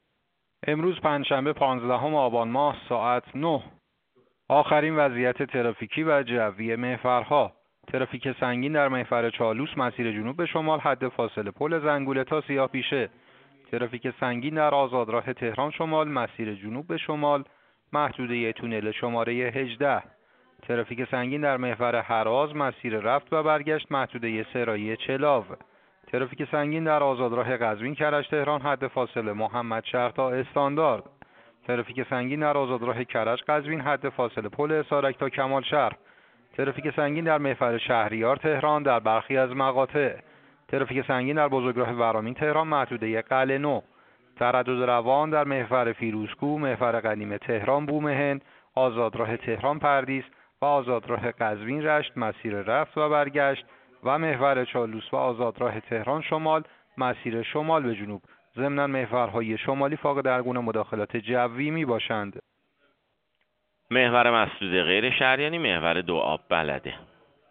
گزارش رادیو اینترنتی از آخرین وضعیت ترافیکی جاده‌ها ساعت ۹ پانزدهم آبان؛